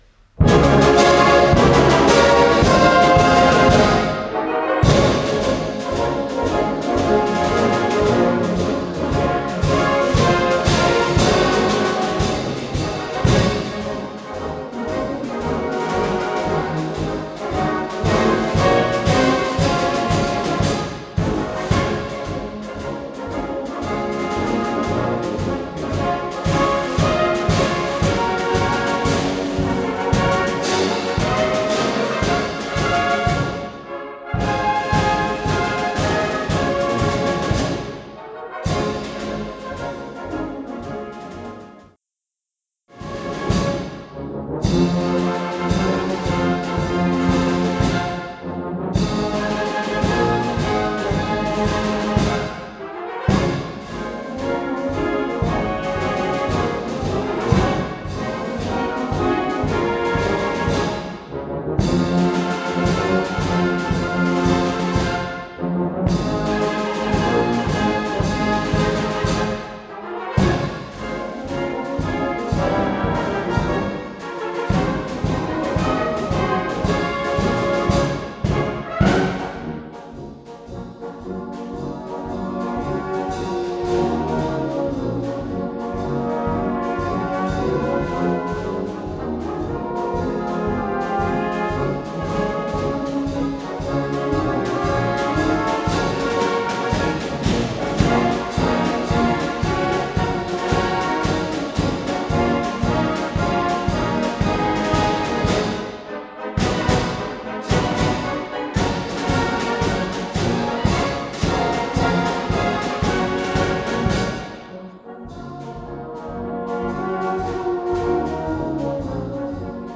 Gattung: Konzertmarsch
Besetzung: Blasorchester
im 6/8-Takt (Trio alla breve).